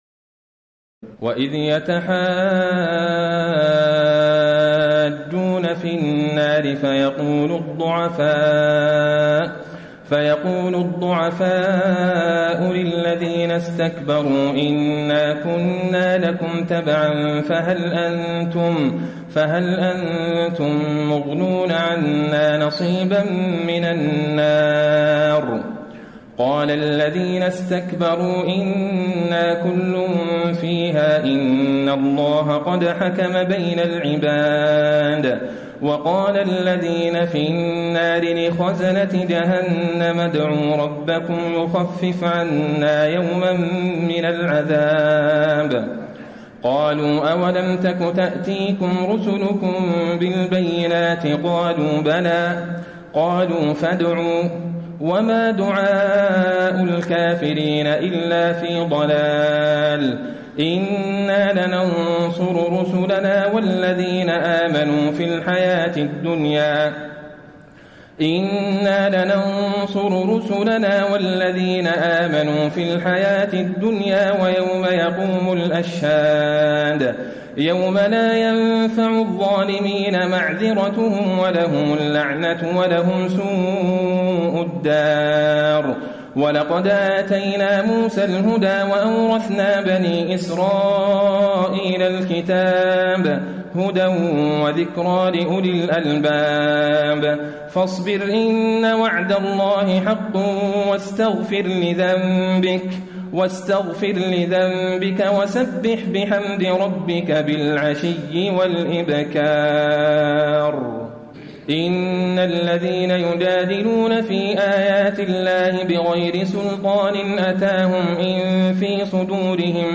تراويح ليلة 23 رمضان 1434هـ من سور غافر (47-85) وفصلت (1-46) Taraweeh 23 st night Ramadan 1434H from Surah Ghaafir and Fussilat > تراويح الحرم النبوي عام 1434 🕌 > التراويح - تلاوات الحرمين